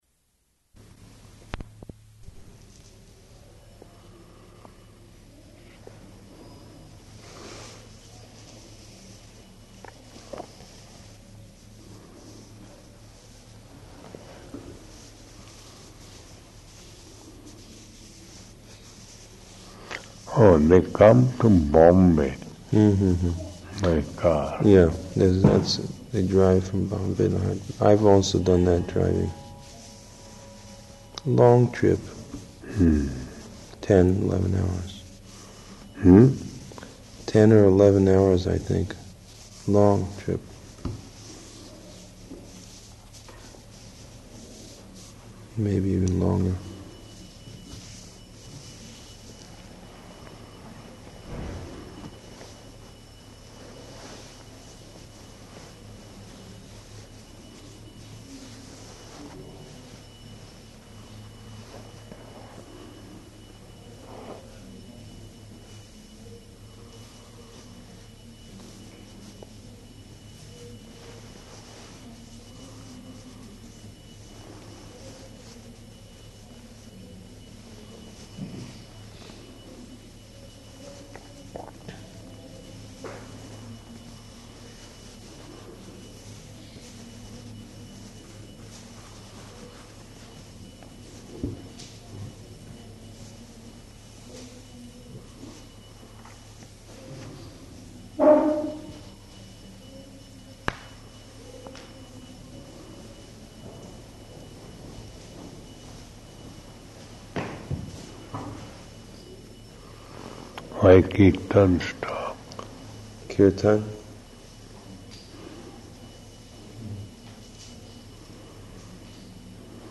-- Type: Conversation Dated: November 3rd 1977 Location: Vṛndāvana Audio file